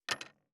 584魚切る,肉切りナイフ,まな板の上,
効果音厨房/台所/レストラン/kitchen食器食材